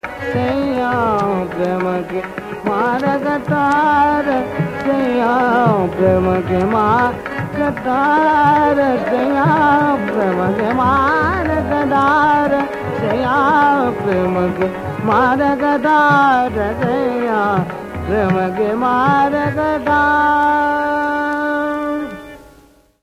laggi section.